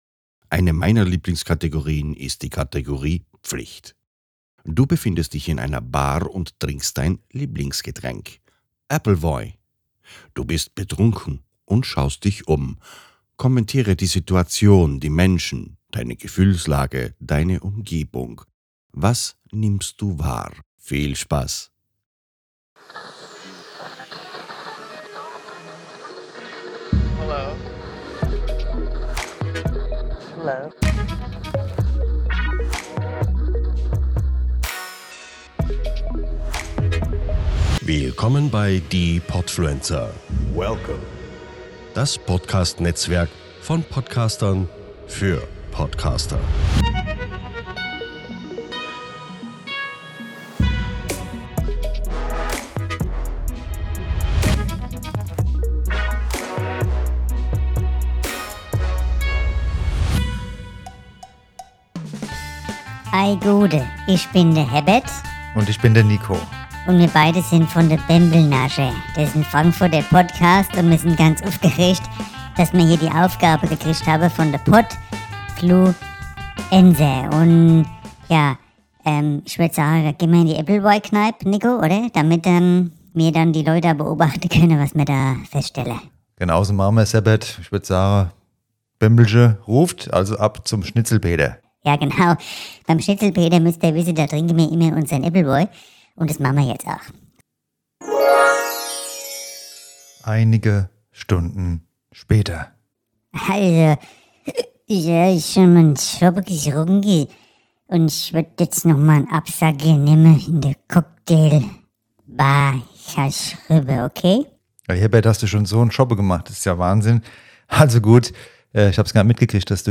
Was er dort sieht, ob er die Tipps der Flirtcoaches umsetzen kann und wie viel Mai Tai er verträgt. Bembelnascher - Frankfurter Lach- und Sachgeschichten ist ein neuer Comedypodcast aus Frankfurt.